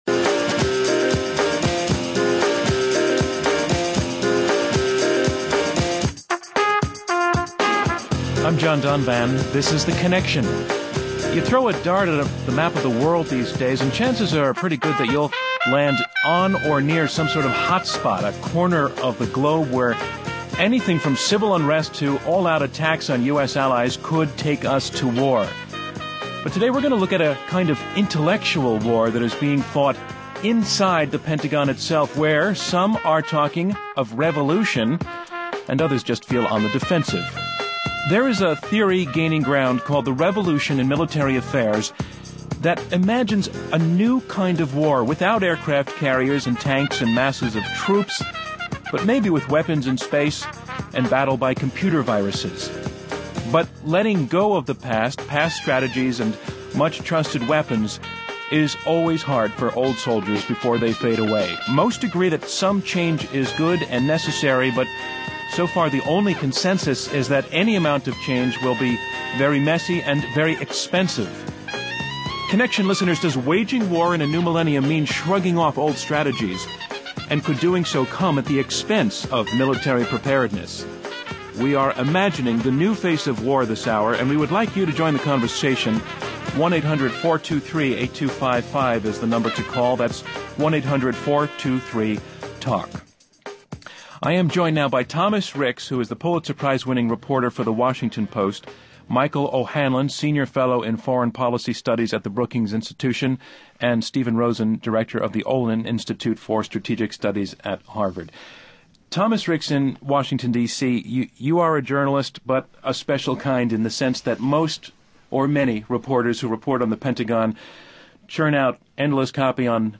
(Hosted by John Donvan)